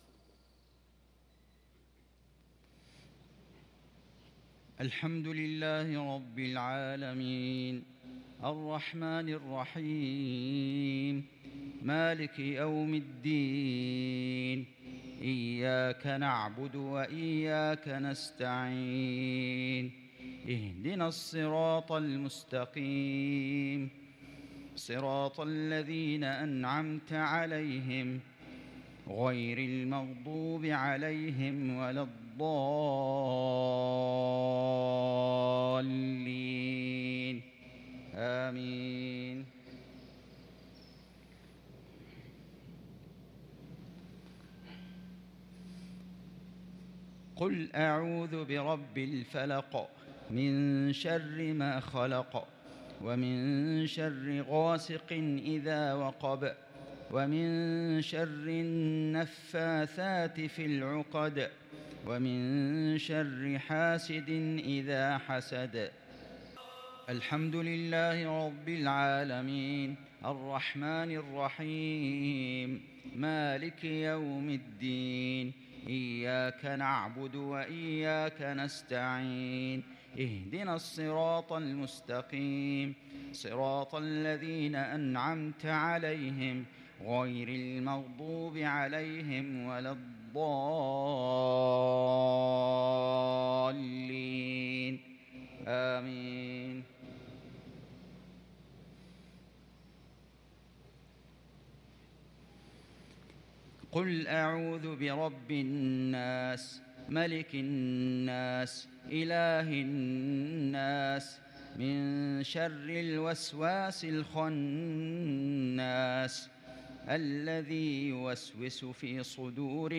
صلاة المغرب للقارئ فيصل غزاوي 17 محرم 1443 هـ